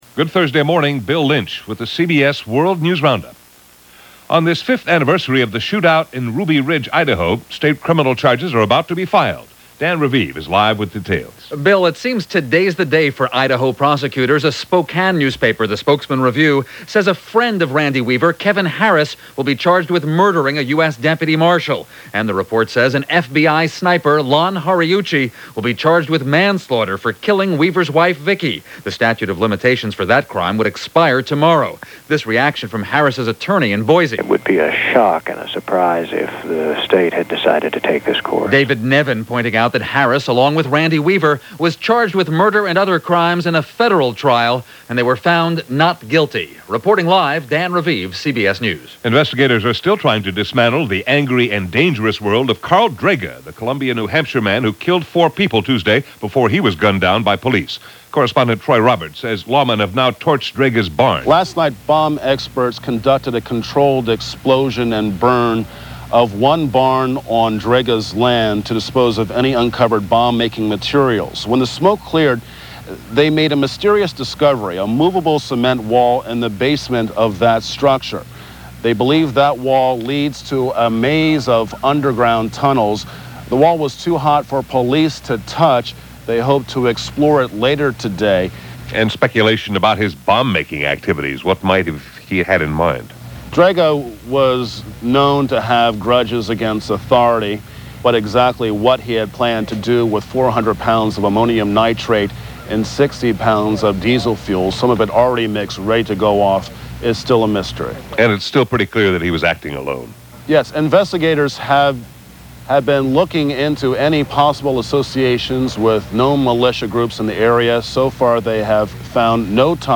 And while the Ruby Ridge hearings continue, that’s just a little of what happened, this 21st of August in 1997 as reported by The CBS World News Roundup.